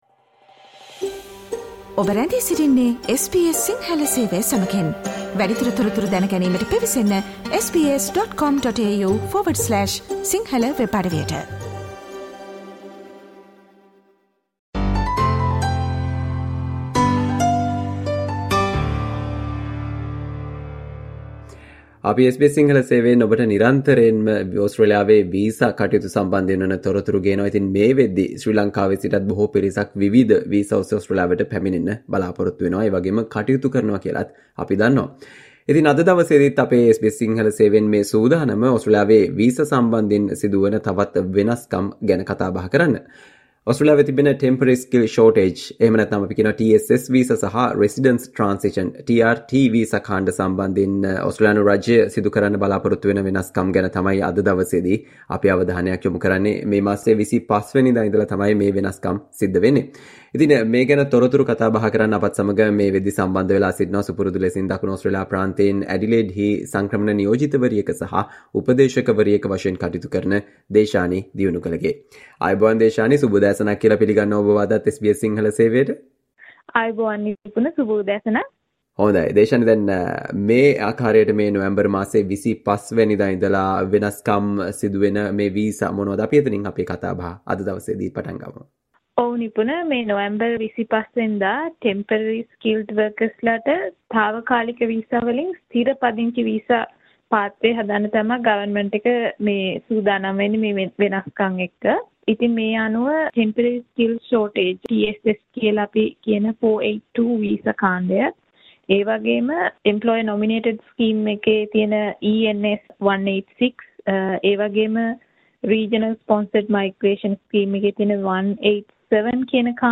SBS Sinhala discussion on changes to TSS and TRT visas to expand pathways to PR for temporary skilled workers